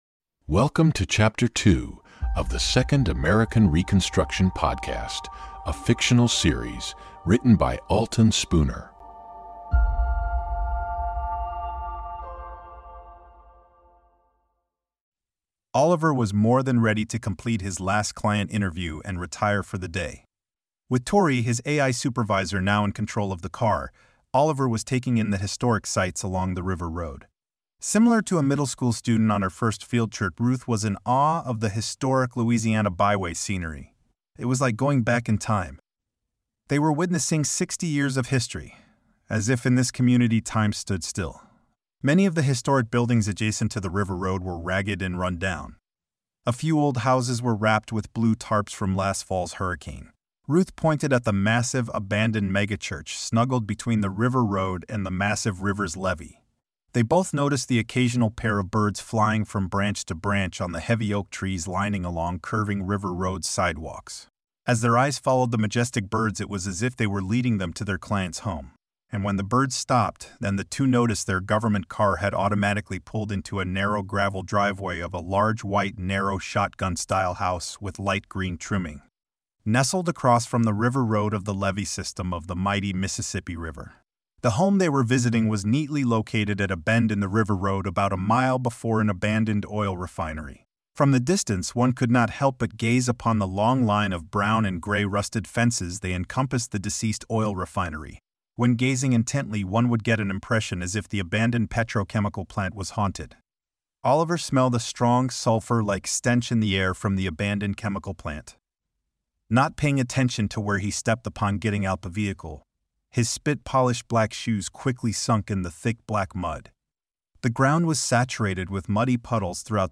Format: Audio Book
Voices: Machine generated
Narrator: Third Person
Soundscape: Voices only